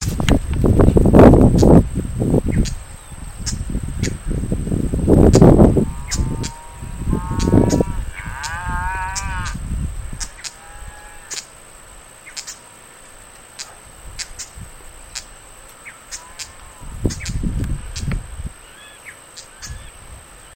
Dot-winged Crake (Laterallus spiloptera)
Repetido "piu-piu" que se escucha detrás del canto del junquero.
El ambiente era únicamente de juncos sin pastizales altos cerca.
Laguna La Cordobesa, partido de Saladillo, provincia de Buenos Aires.
Condition: Wild
Certainty: Recorded vocal